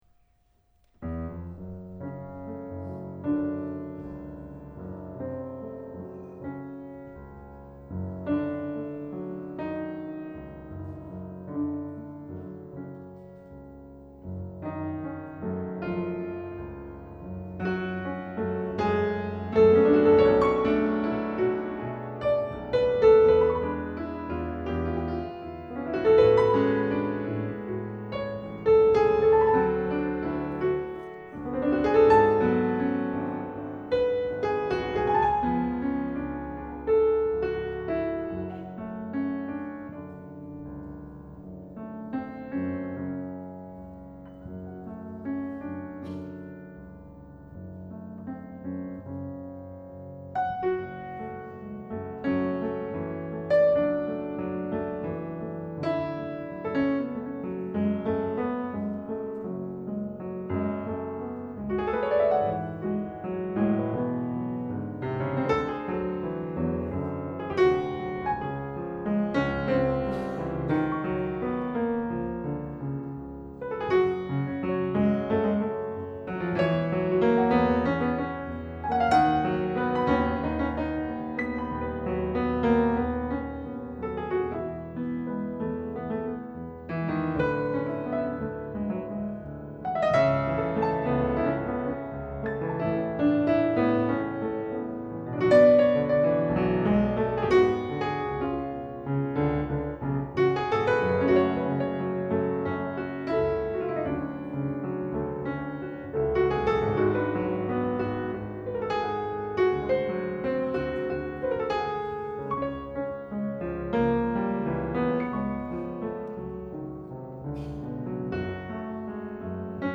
virtuosic two-piano arrangements
Live at Gilmore Festival